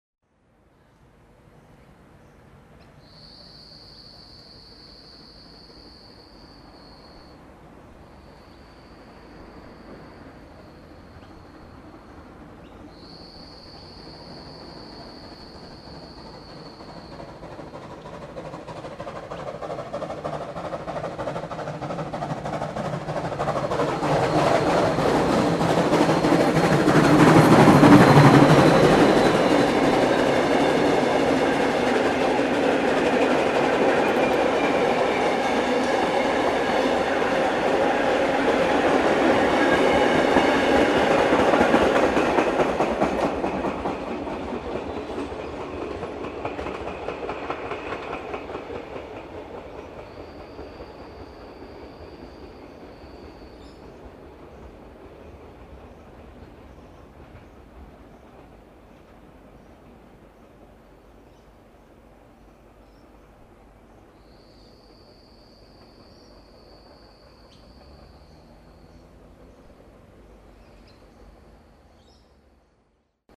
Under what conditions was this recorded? Unfortunately, on this day the thing decided to generate a sporadic buzzing sound which ruined the on-train recording, but I was able to get a lineside recording at the usual spot at Tennyson. TennysonLS1stloop.mp3